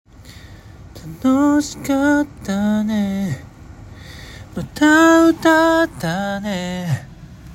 ②ブレスアウト
①のぶつ切りに近いのですが、息で終わるパターンです。
やり方としては「あーーーーー」と伸ばして、あるところを境に一気に息を吐いて終わらせるという感じです。